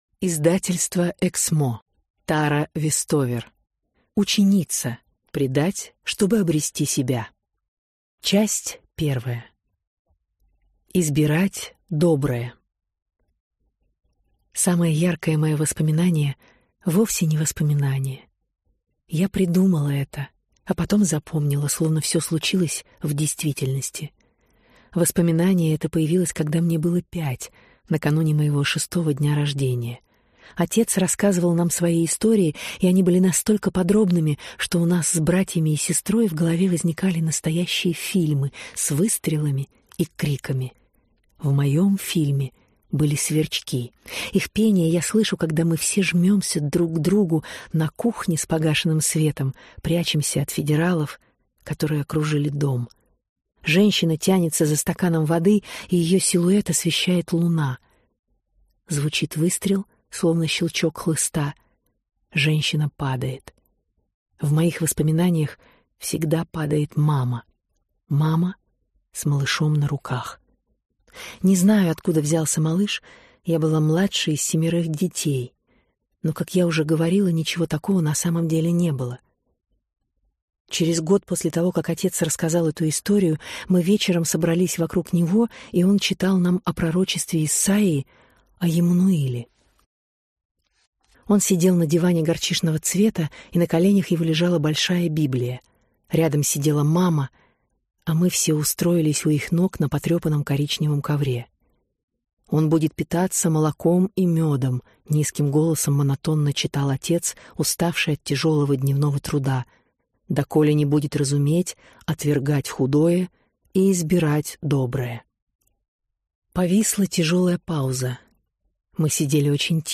Аудиокнига Ученица. Предать, чтобы обрести себя | Библиотека аудиокниг